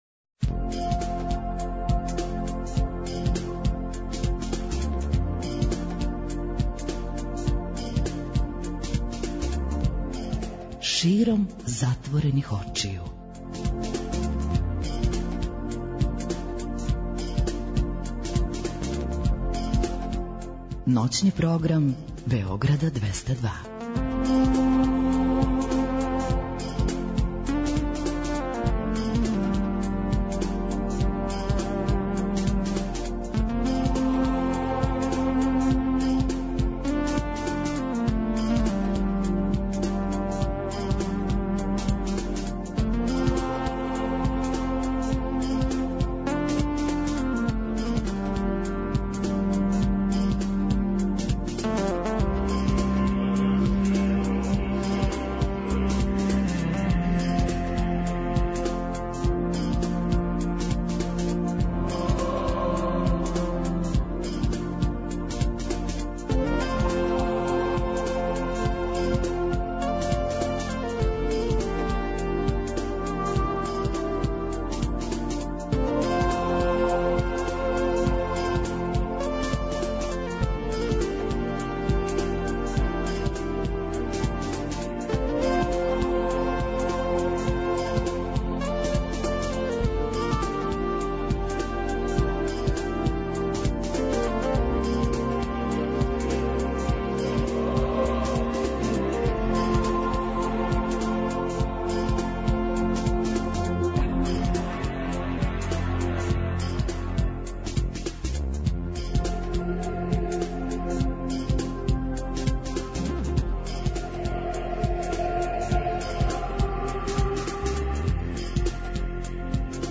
Ноћни програм Београда 202
Слушаоци и редакција омиљене радио станице у Србији представљају један тим под називом «Сањалице», ове ноћи подељени у две екипе - плави и црвени. Плави тим поставља питања из опште културе, а црвени тим одговара.